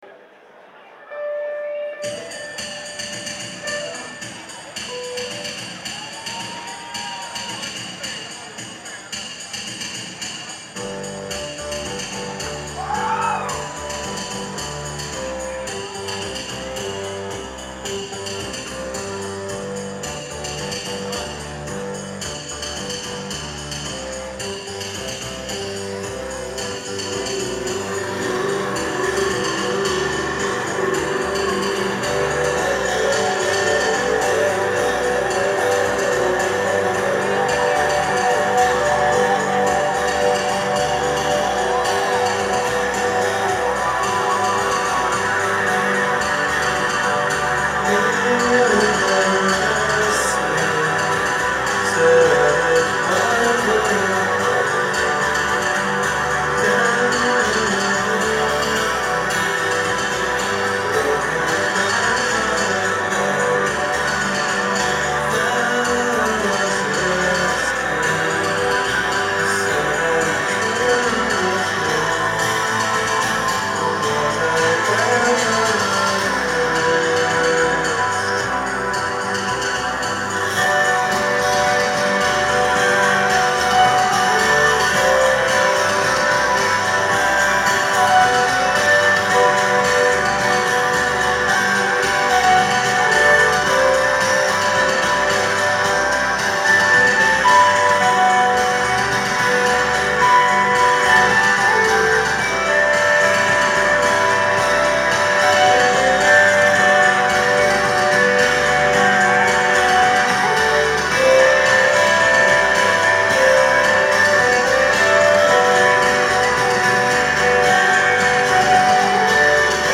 Live at the Bell House
in Brooklyn, NY